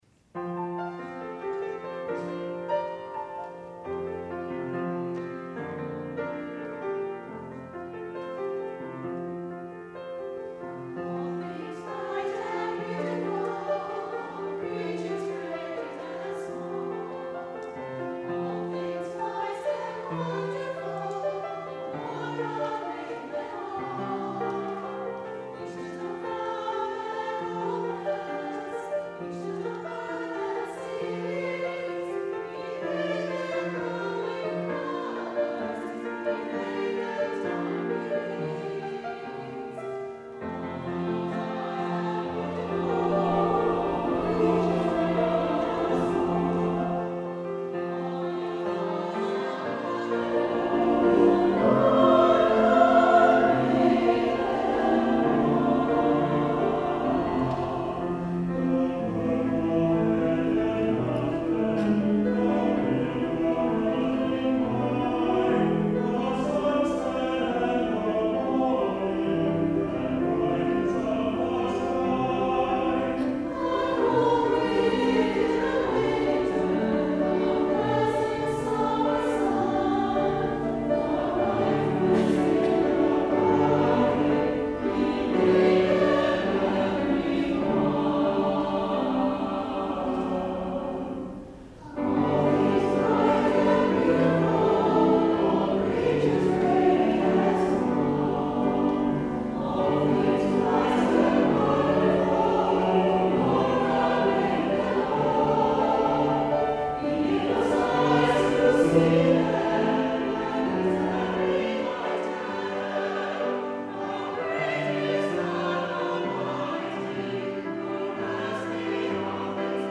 Combined Junior and Chancel Choirs
organ